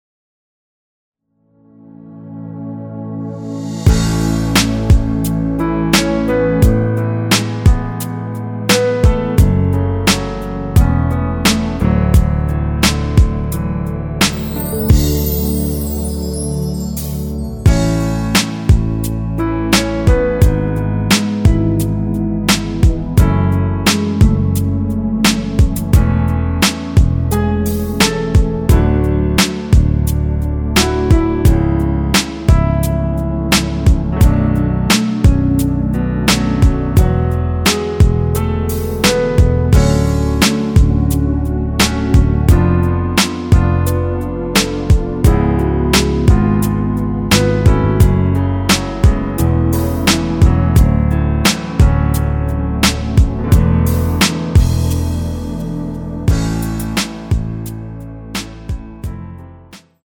원키에서(-3)내린 MR입니다.
앞부분30초, 뒷부분30초씩 편집해서 올려 드리고 있습니다.
중간에 음이 끈어지고 다시 나오는 이유는